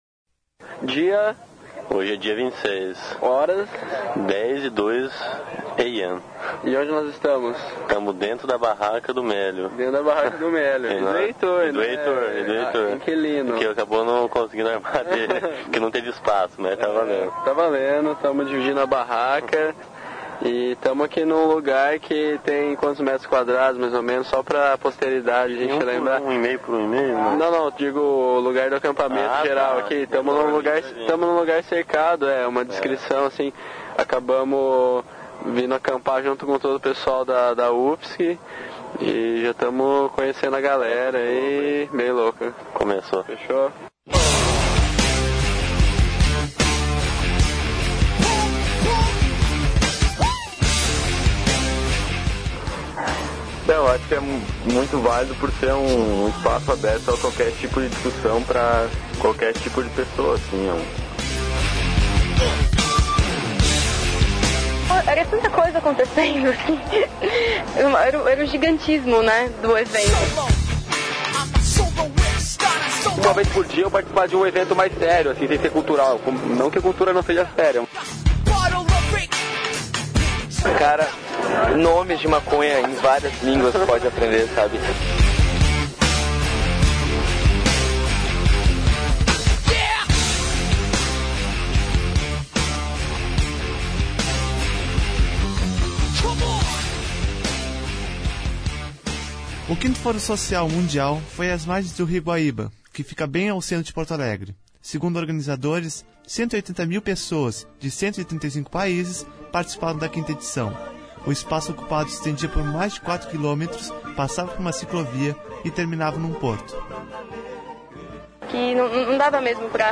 Show full item record Title: Fórum Social Mundial de 2003 Abstract: Depoimentos de pessoas que estiveram presentes no Fórum Social Mundial de 2003, em Porto Alegre.
Documentário